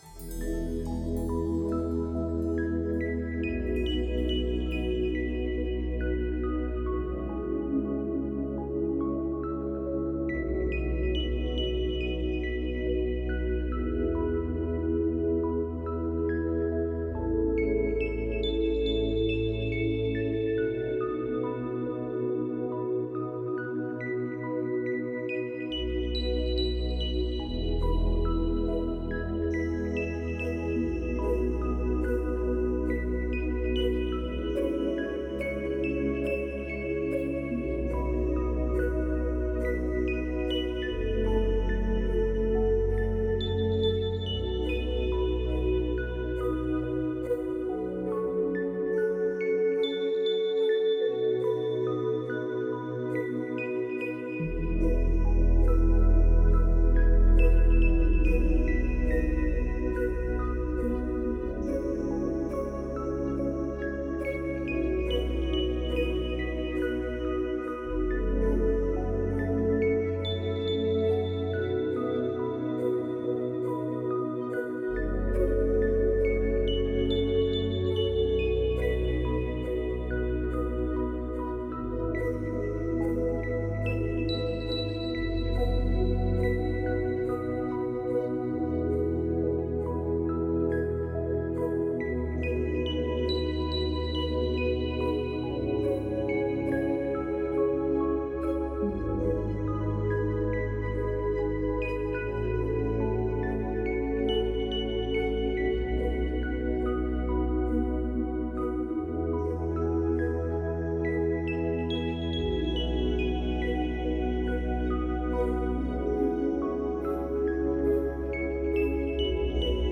Жанр: Soundtrack.